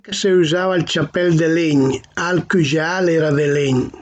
Itè par cà | Dialetto di Albosaggia